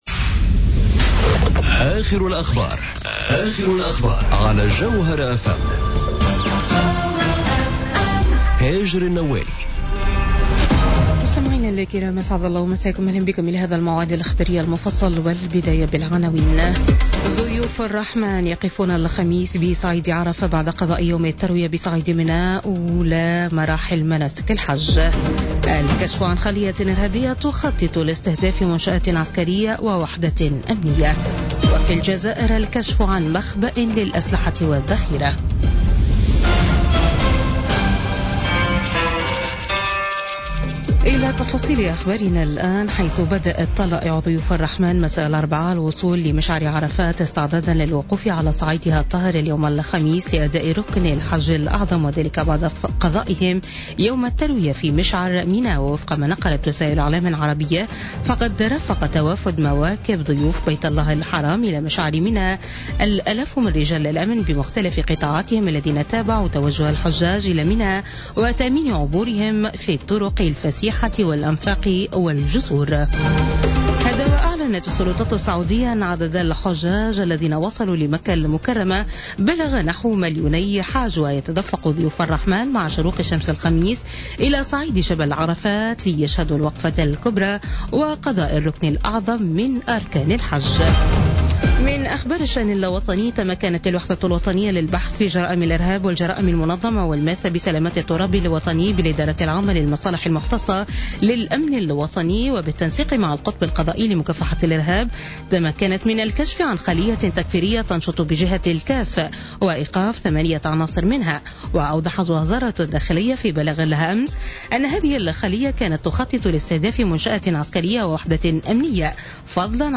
نشرة أخبار منتصف الليل ليوم الخميس 31 أوت 2017